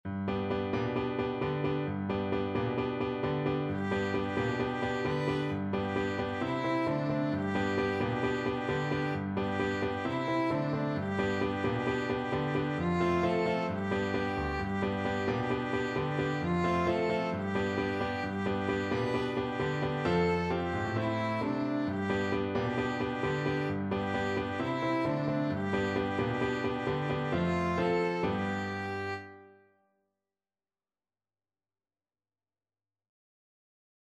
4/4 (View more 4/4 Music)
Fast =c.132
world (View more world Violin Music)